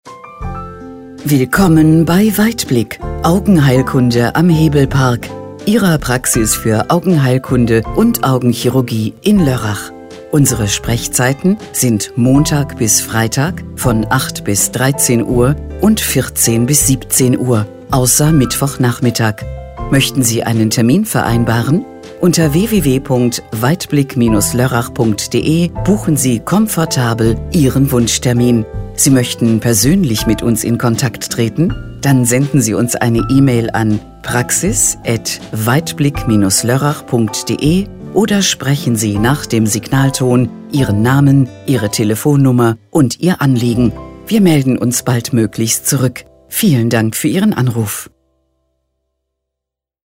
Anrufbeantworter Ansage Augenarzt: